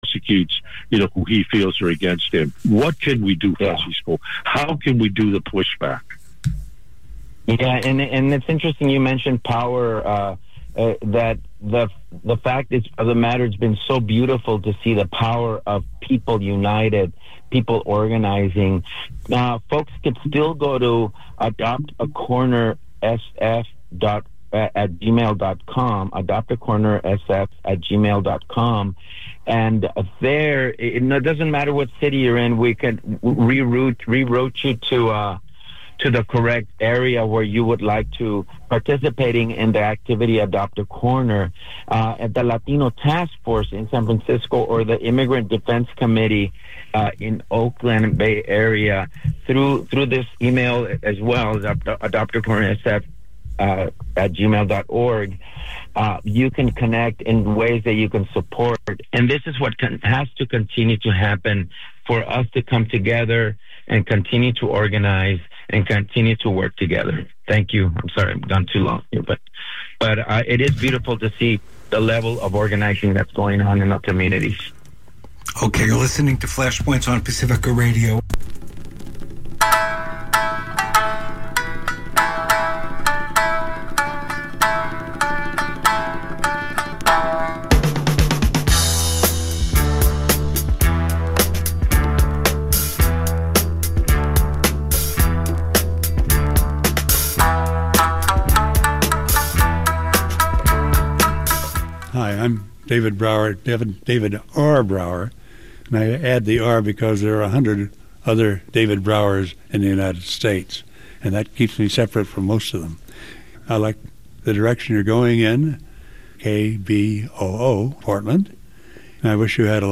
Views, Reviews, and Interviews from a socialist-feminist, anti-racist and anti-colonial point of view.